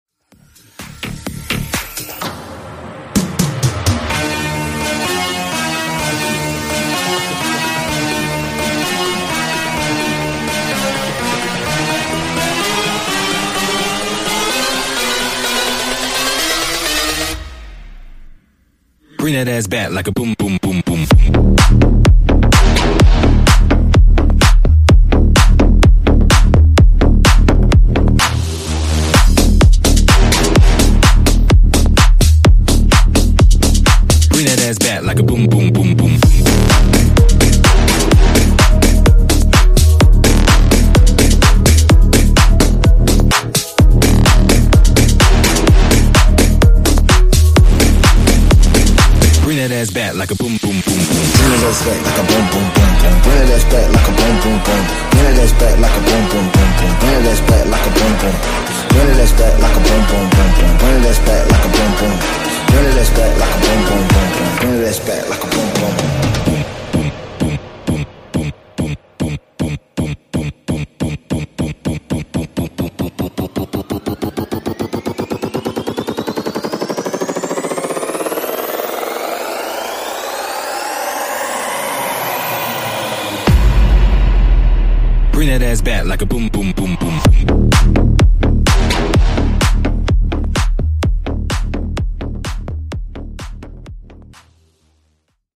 Genres: RE-DRUM , REGGAETON
Clean BPM: 105 Time